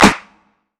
Toxic ClapSnare.wav